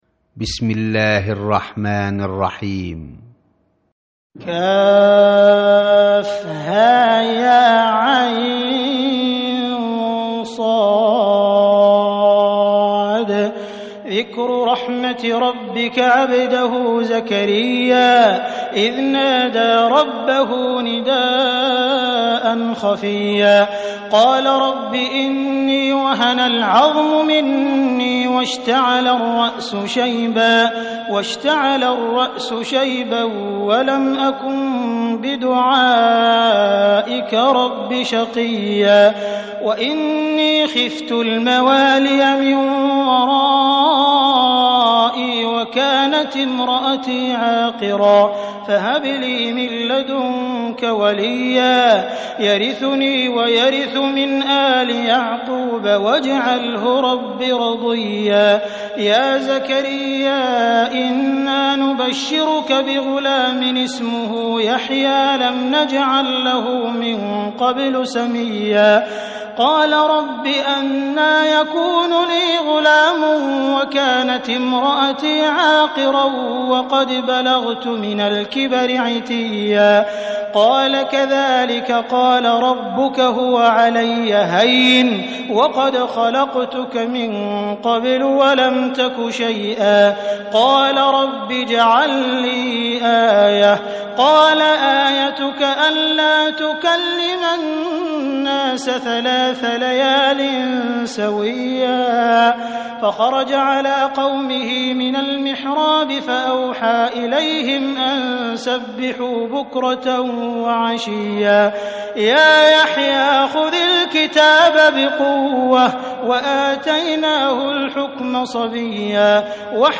récitation mp3 - Hafidhun Sudais wa Shuraym (qdlfm) - 8 934 ko ;
019-Surat_Mariam_(Marie)_Cheikh_Sudais_Wa_Shuraym.mp3